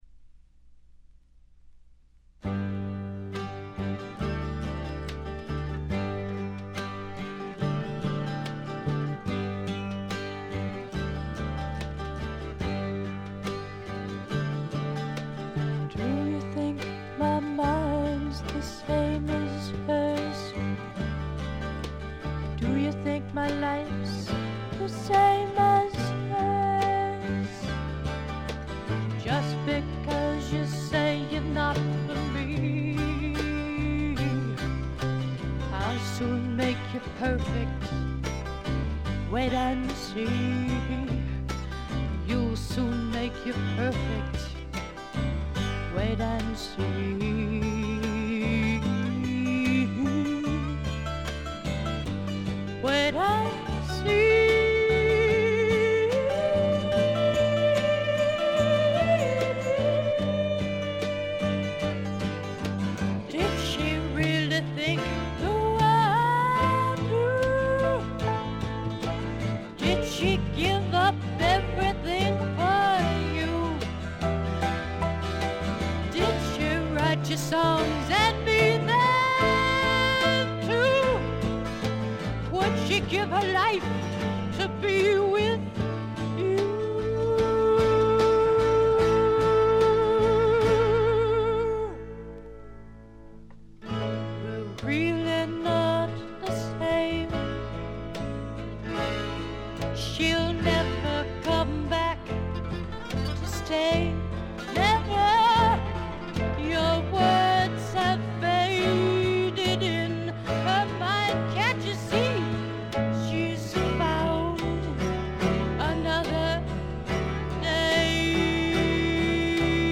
わずかなノイズ感のみ。
試聴曲は現品からの取り込み音源です。
Vocal, Guitar